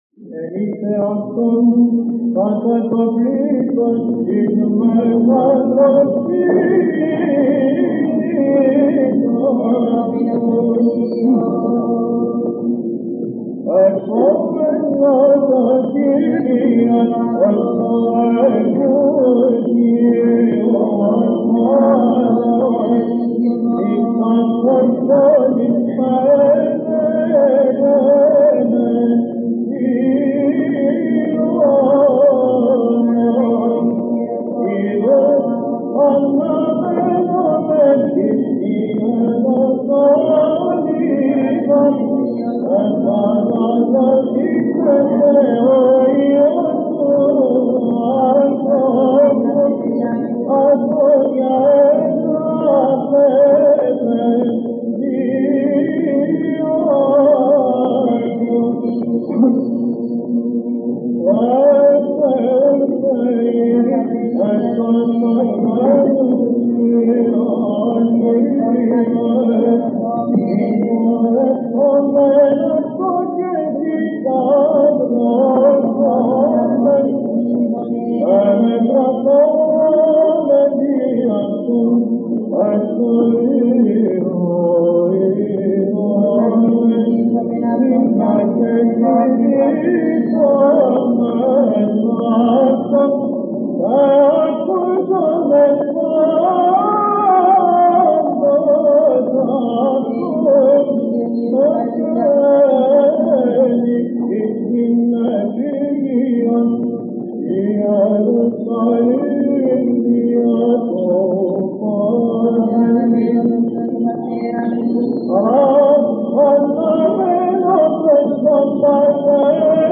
ἔτος 1959 (ἠχογρ. Κυρ. Βαΐων ἑσπέρας)